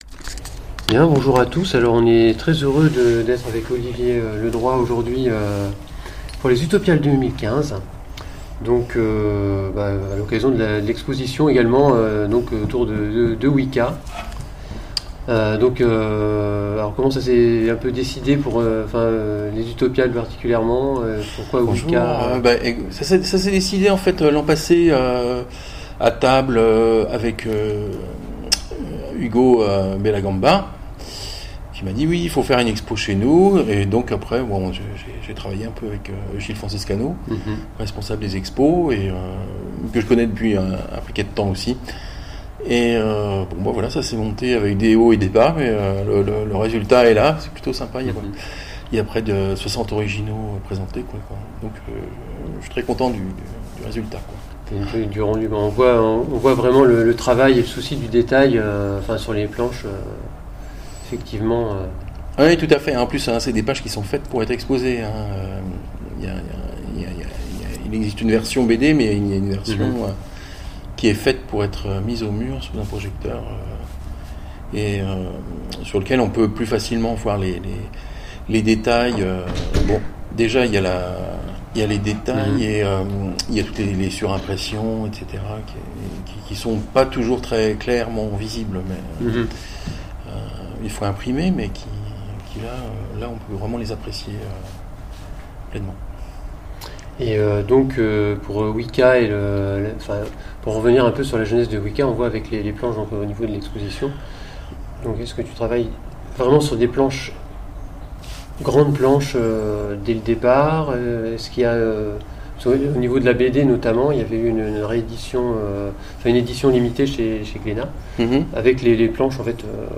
Interview d'Olivier Ledroit sur Wika